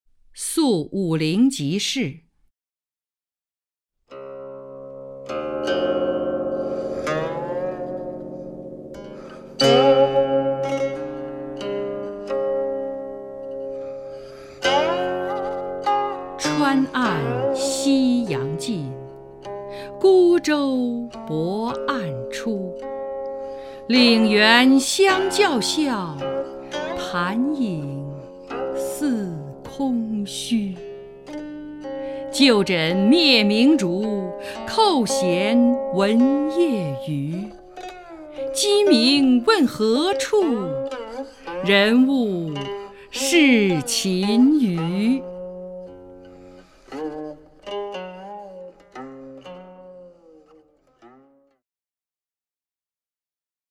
张筠英朗诵：《宿武陵即事》(（唐）孟浩然)　/ （唐）孟浩然
名家朗诵欣赏 张筠英 目录